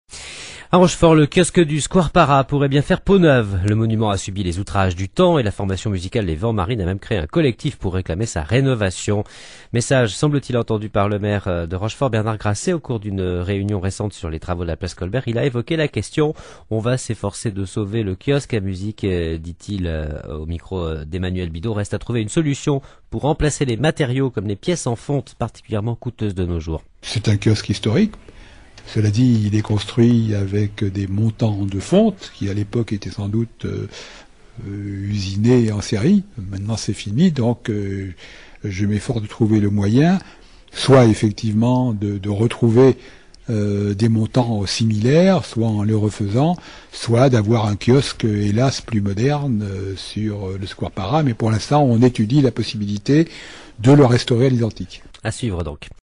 9 Mai 2006 - Entendu dans le poste....
Extrait du journal du 9 Mai 2006